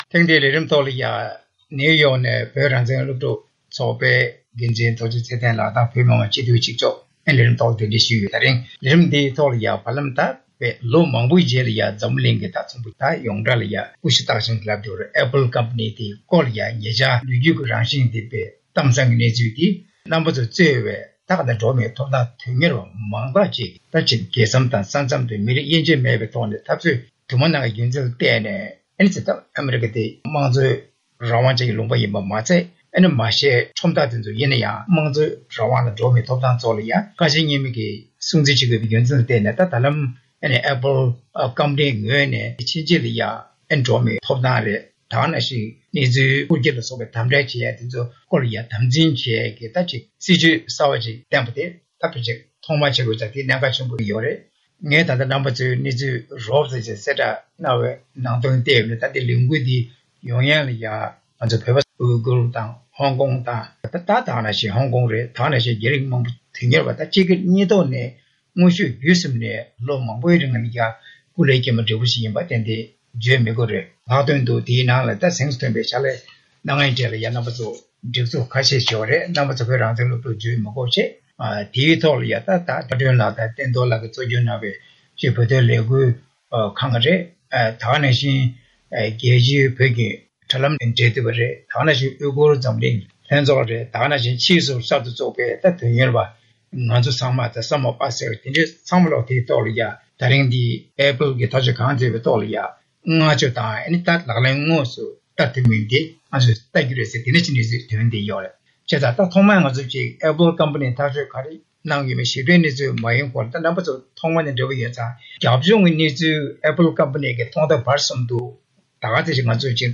གླེང་མོལ་ཞུས་པར་གསན་རོགས།།